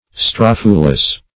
strophulus - definition of strophulus - synonyms, pronunciation, spelling from Free Dictionary Search Result for " strophulus" : The Collaborative International Dictionary of English v.0.48: Strophulus \Stroph"u*lus\, n. [NL.]